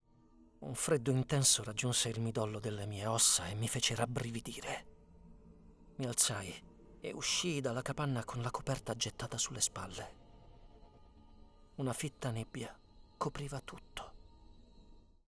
Male
young adult male,
smooth
Audiobooks
Warm Engaging Storytelling
0402IT_Audiobook_Storytelling.mp3